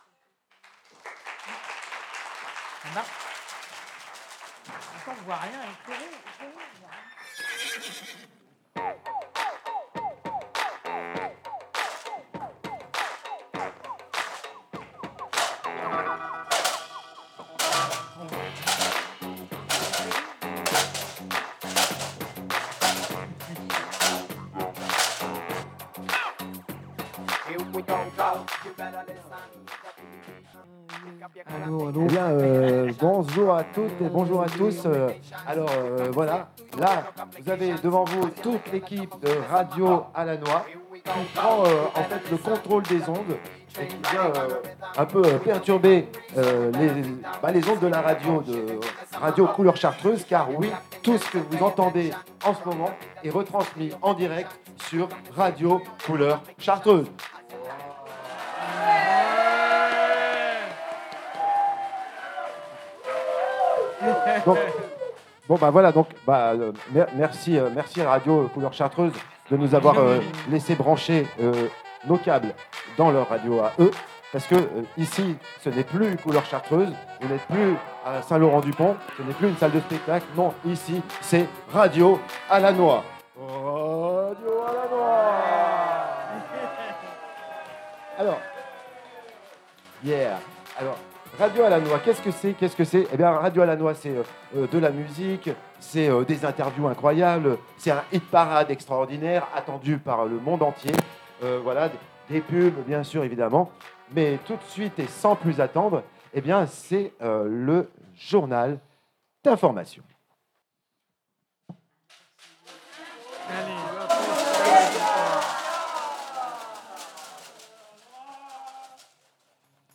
Le centre hospitalier de St Laurent du Pont et la Compagnie Naüm ont représenté le spectacle Radio à la noix le 13 décembre 2025 dans la maison des arts de St Laurent
Le spectacle a pu être retranscrit en direct sur les ondes radio couleur chartreuse